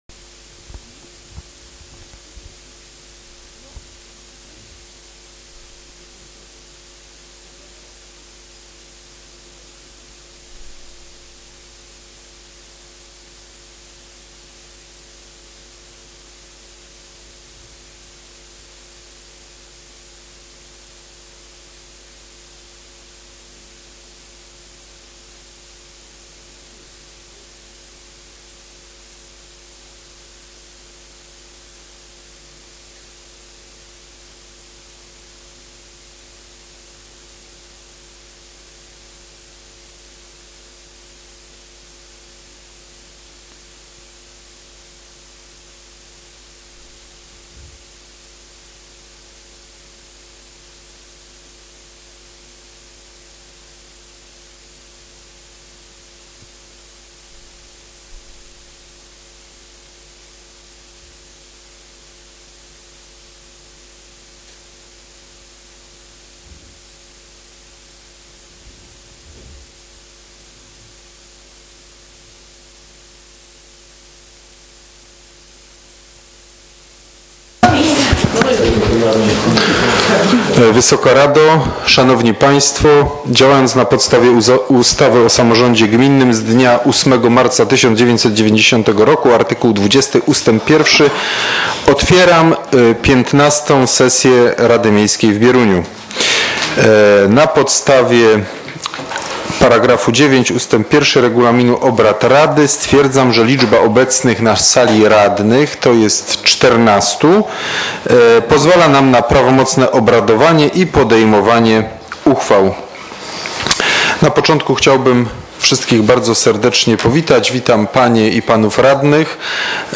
z obrad XV sesji Rady Miejskiej w Bieruniu, która odbyła się w dniu 22.11.2018 r.
Załączony plik „Nagranie” zawiera pełny przebieg sesji w wersji fonicznej.